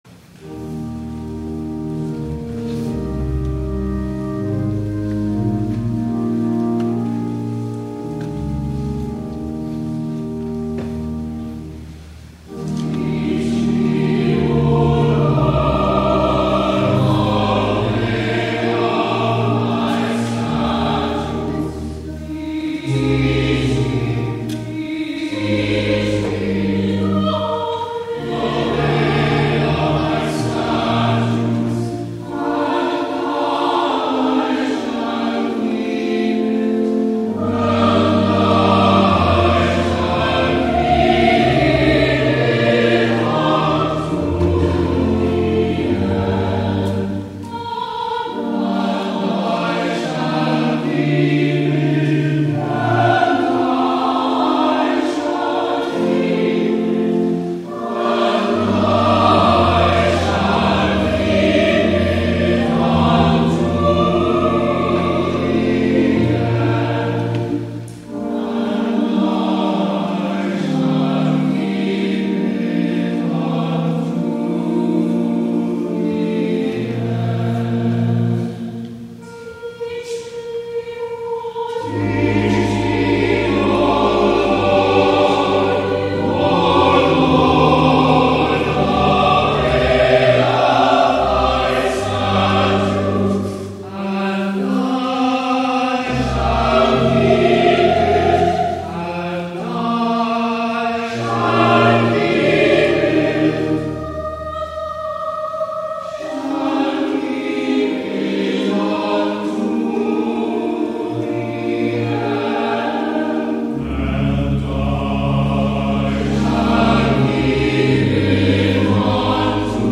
Bulletin for July 27, 2014
THE ANTHEM